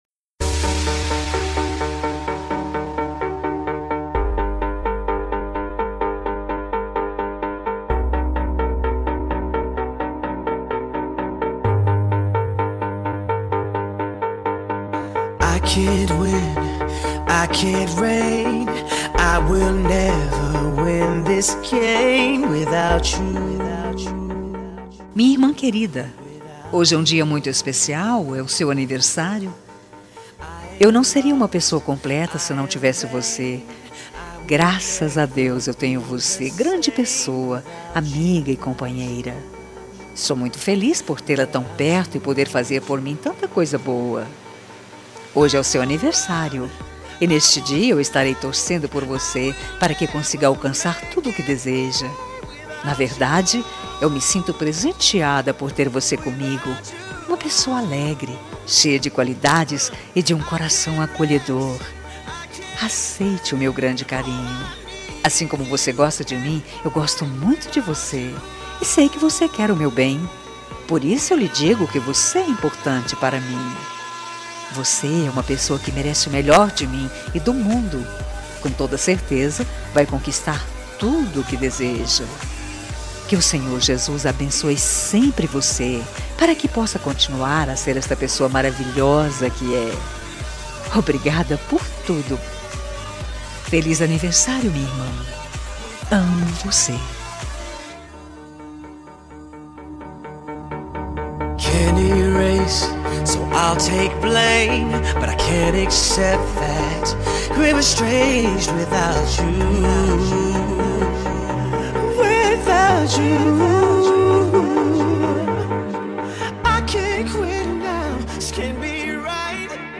Telemensagem Aniversário de Irmã – Voz Feminina – Cód: 20226